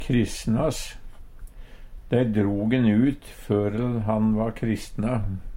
kristnas - Numedalsmål (en-US)